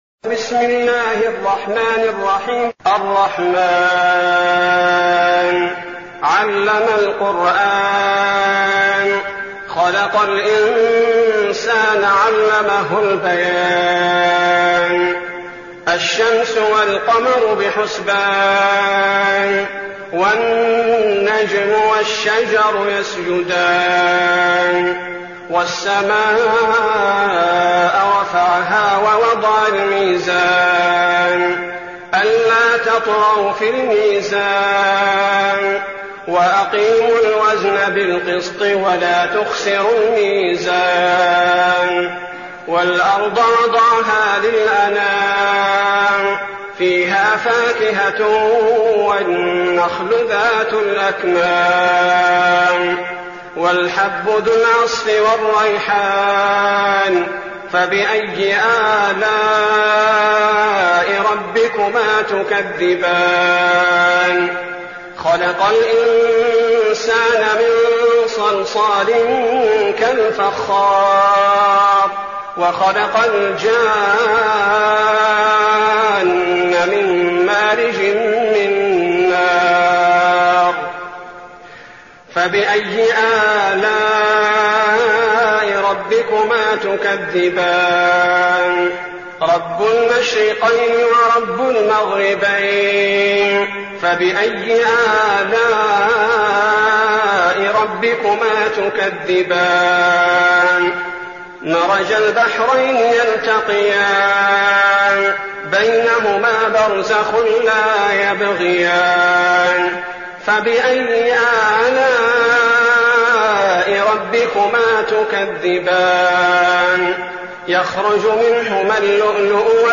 المكان: المسجد النبوي الشيخ: فضيلة الشيخ عبدالباري الثبيتي فضيلة الشيخ عبدالباري الثبيتي الرحمن